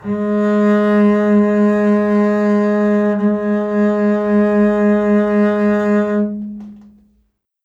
contrabass
Gs3.wav